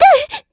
Ripine-Vox_Damage_kr_02.wav